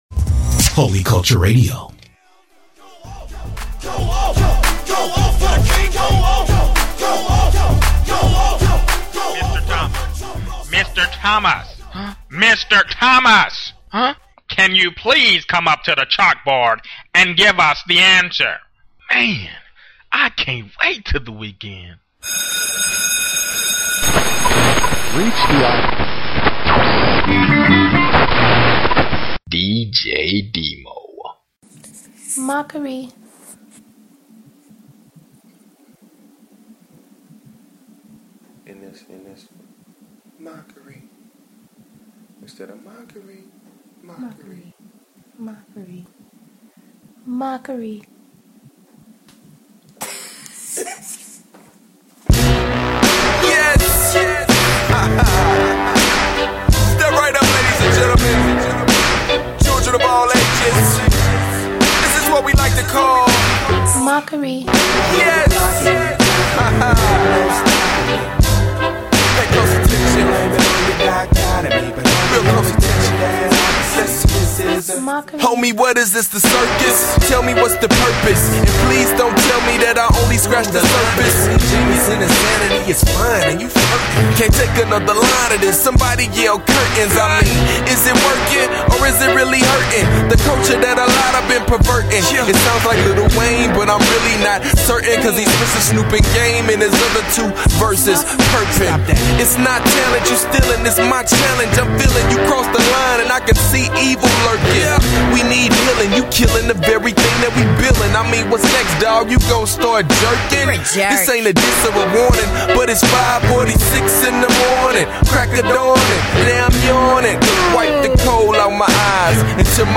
New Music, more exclusives, Hot Mixing….What more to say!